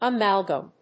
音節a・mal・gam 発音記号・読み方
/əmˈælgəm(米国英語)/
フリガナアマルガム